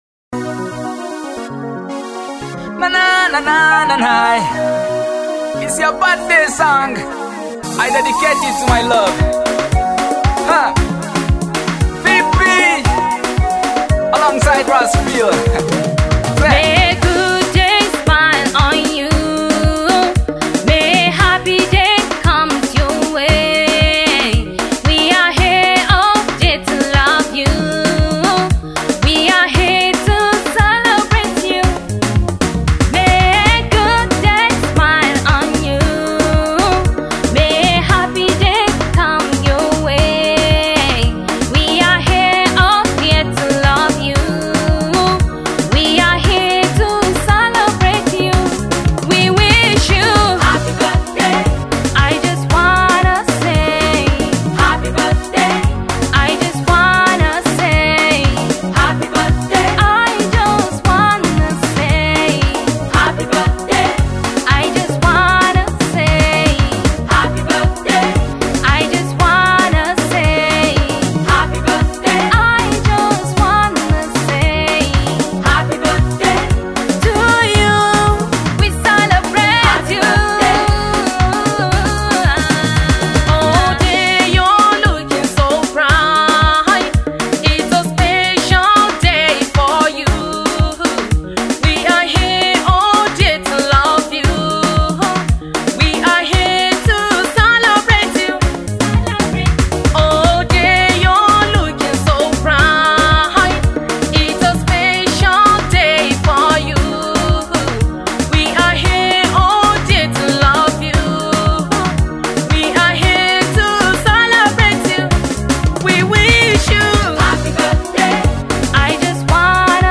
Nigerian gospel music duo and kalangu  singers